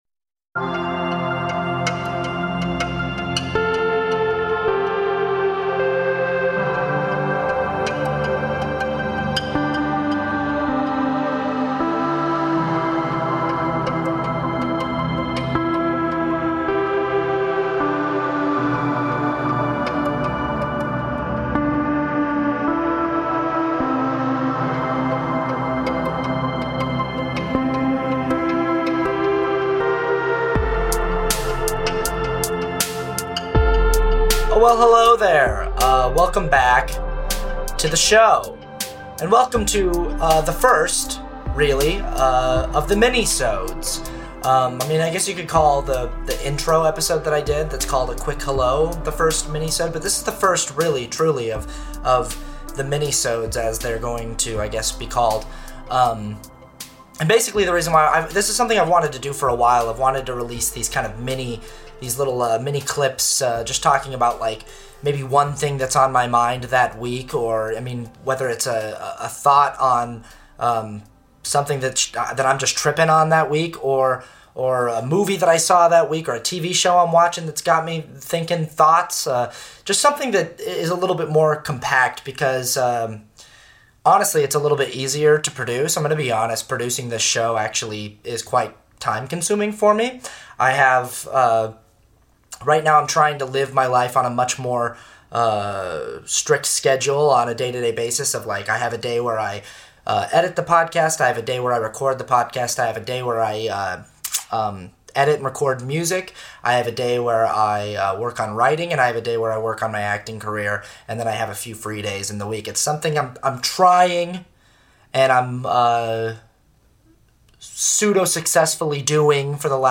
It's just me. Talking about a few things that are on my mind this week.